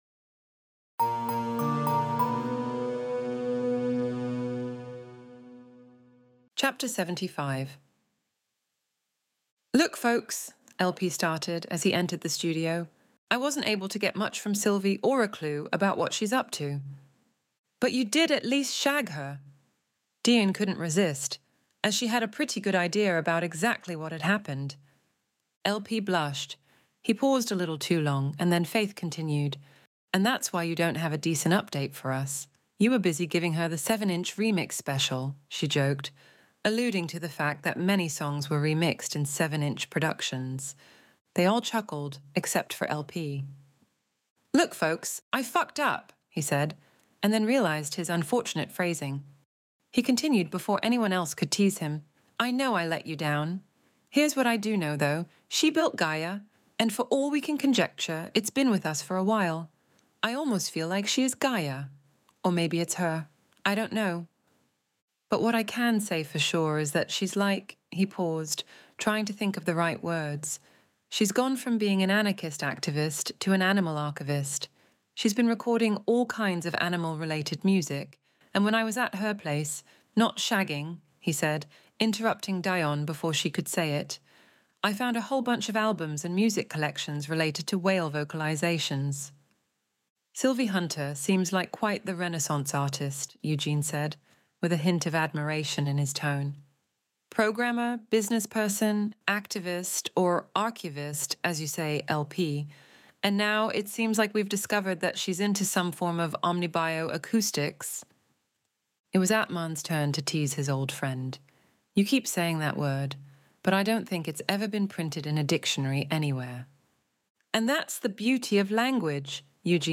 Extinction Event Audiobook Chapter 75